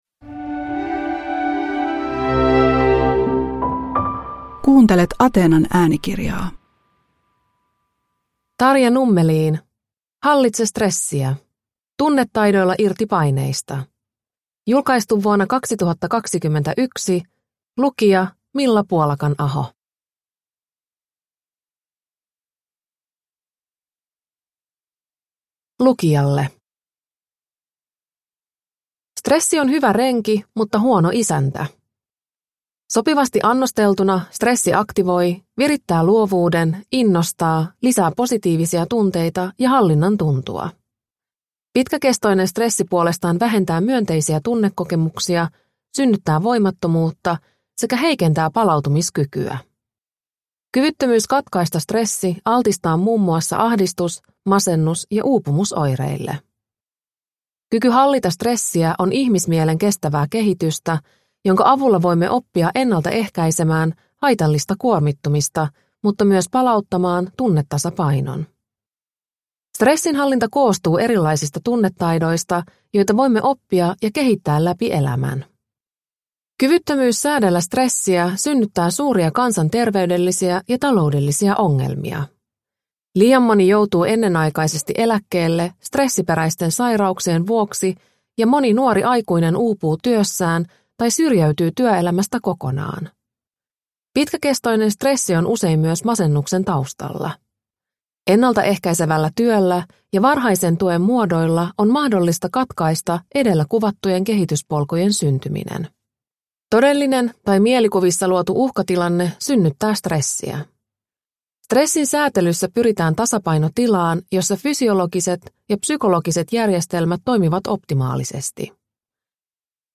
Hallitse stressiä – Ljudbok – Laddas ner